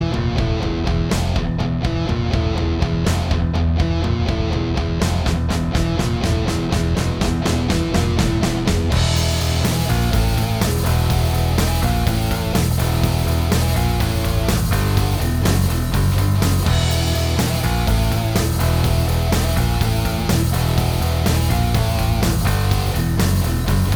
Minus All Guitars Rock 5:29 Buy £1.50